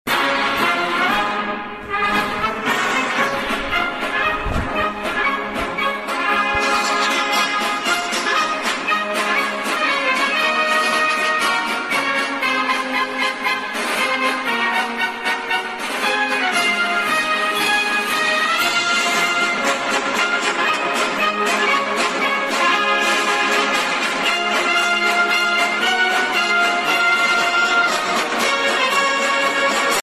marsh.mp3